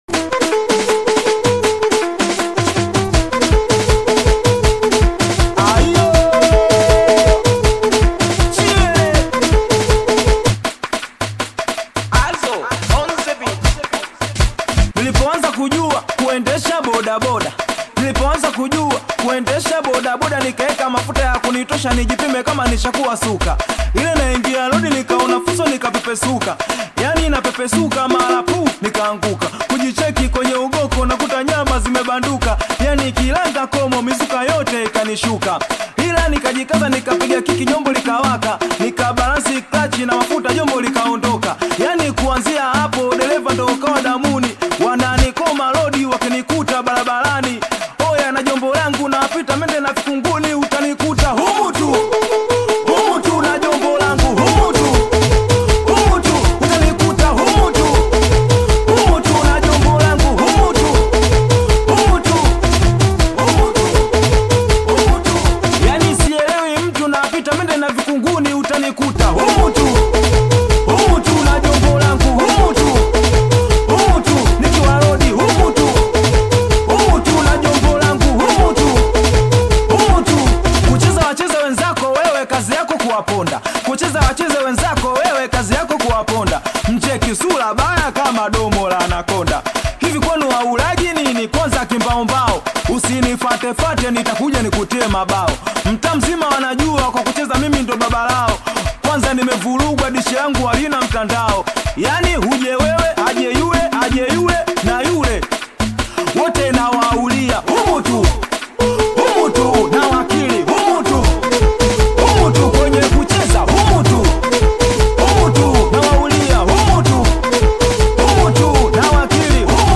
Singeli music track
Tanzanian Bongo Flava singeli artists